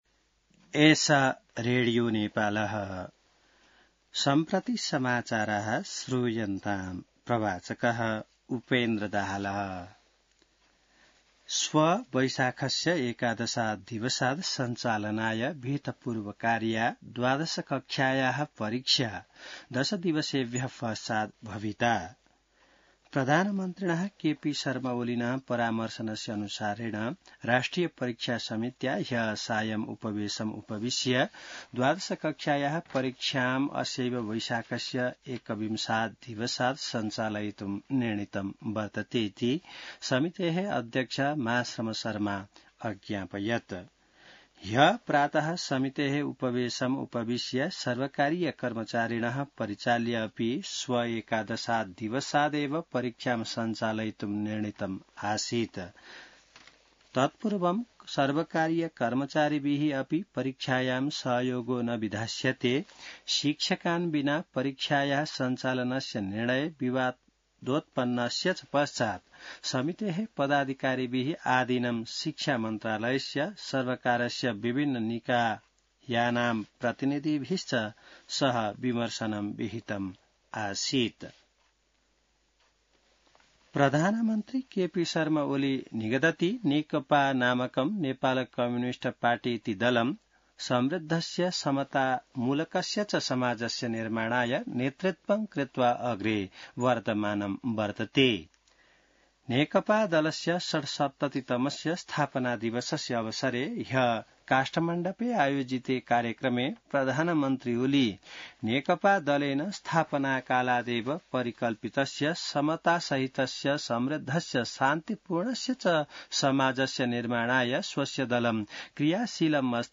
An online outlet of Nepal's national radio broadcaster
संस्कृत समाचार : १० वैशाख , २०८२